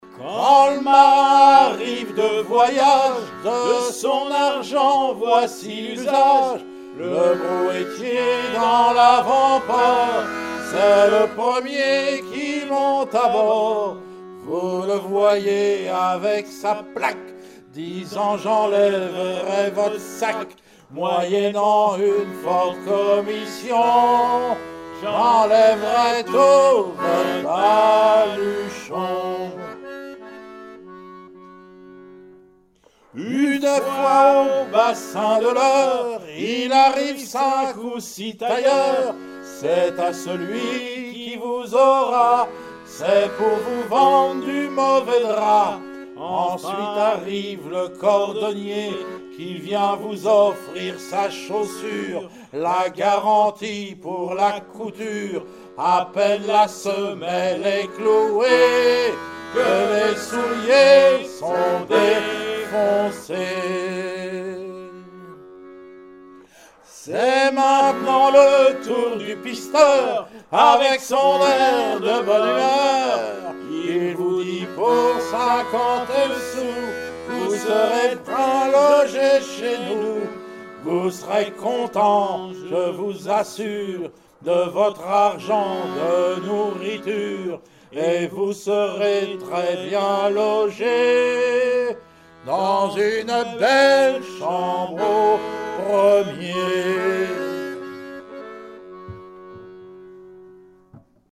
circonstance : maritimes
Pièce musicale inédite